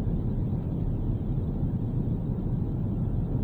spaceship_engine_boosted_loop.wav